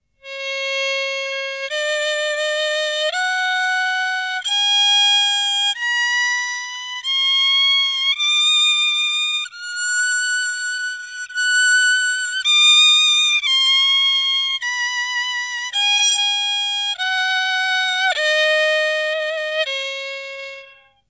Đàn cò líu
[IMG]àn Cò Líu trong trẻo, chói sáng, biểu đạt những tình cảm đẹp đẽ, cao thượng, vui tươi, sôi nổi... có thể gay gắt, sắc nhọn nhất là ở những âm cao.